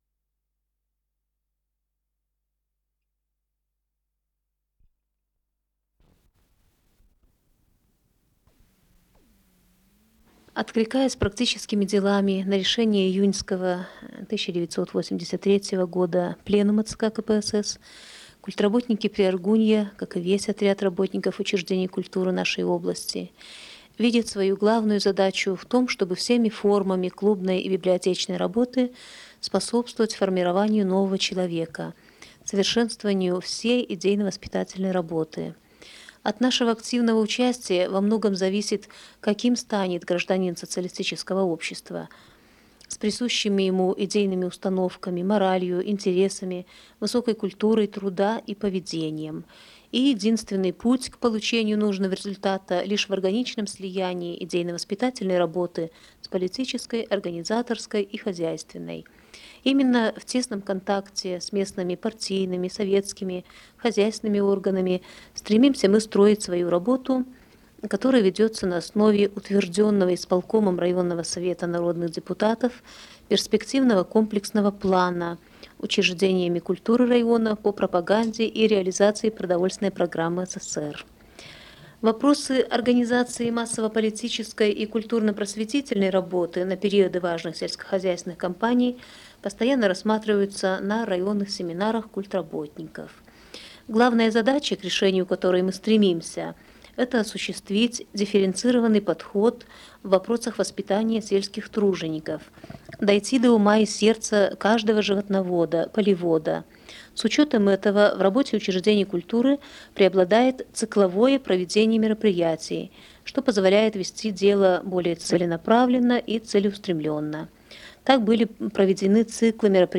Формуляр Название передачи Отрывки интервью Код ЧС-006 Фонд Комитет по телевидению и радиовещанию Читинского облисполкома Редакция Документальная Общее звучание 00:32:25 Дата добавления 12.01.2023 Прослушать